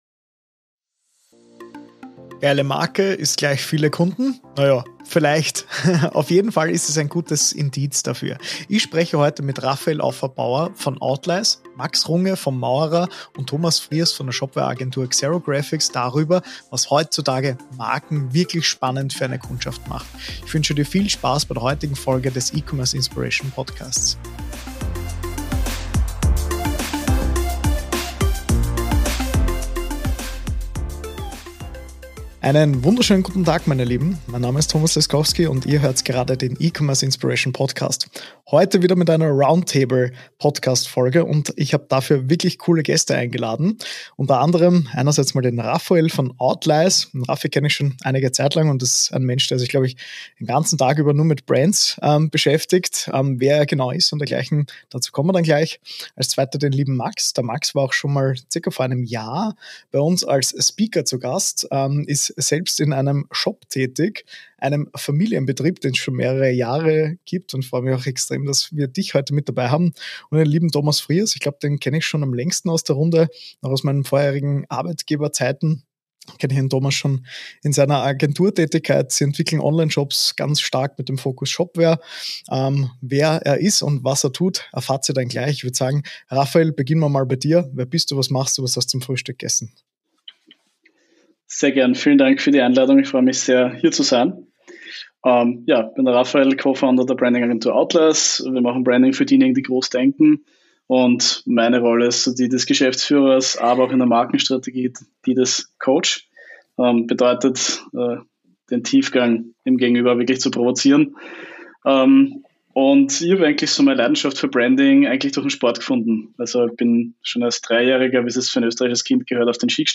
Branding Roundtable